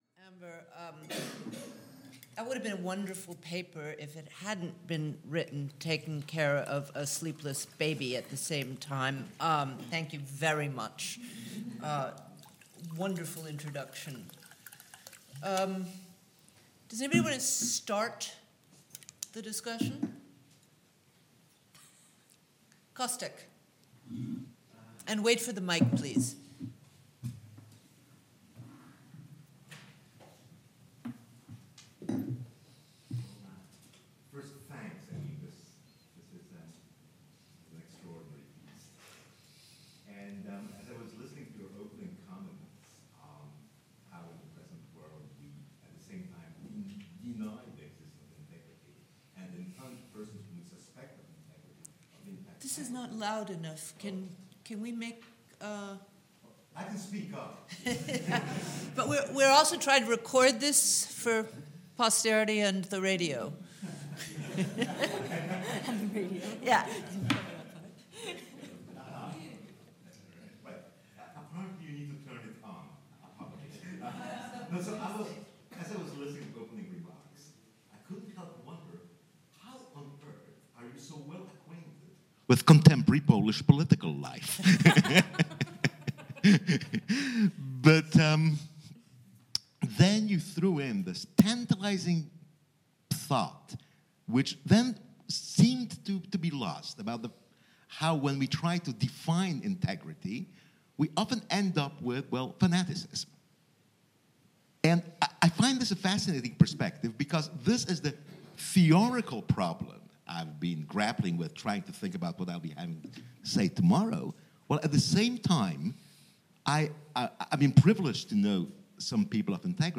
The following paper was presented at Saints and Madmen: Integrity at its Limits, at the Einstein Forum in June 2014.